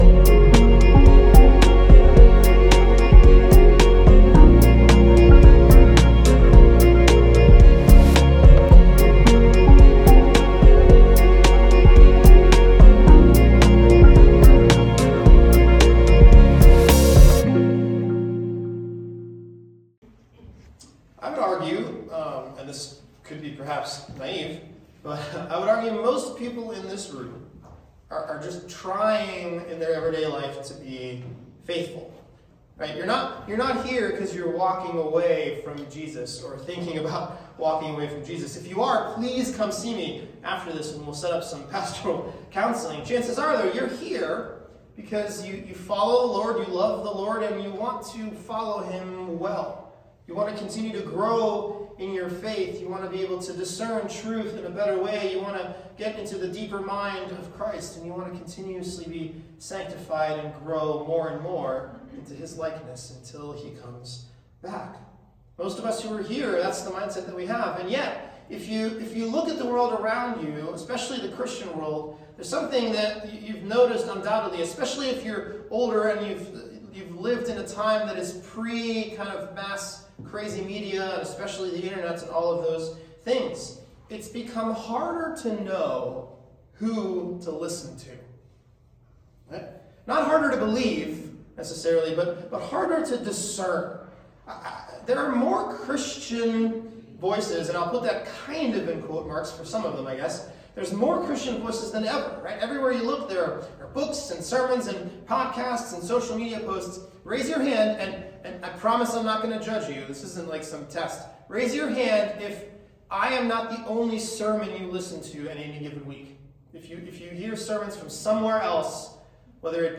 SPC Sermons